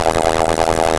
RbtStingerAmb.wav